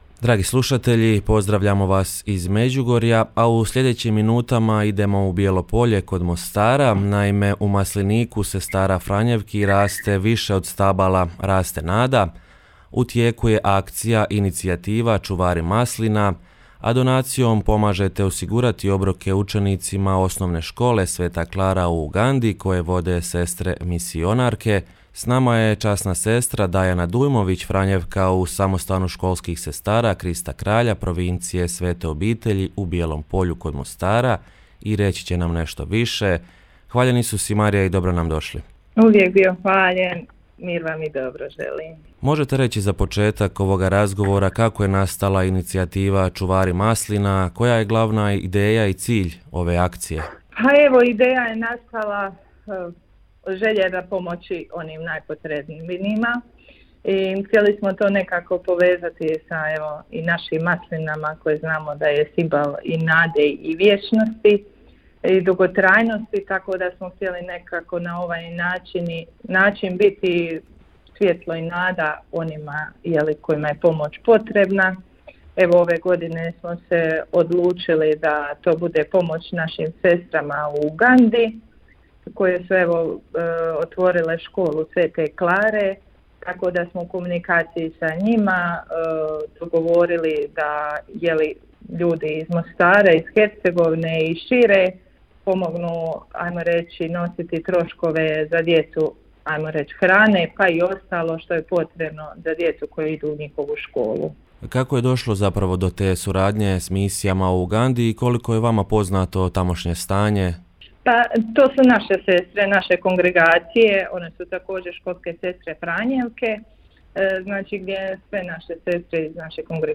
Prilog-Inicijativa-Cuvari-maslina.mp3